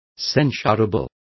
Complete with pronunciation of the translation of censurable.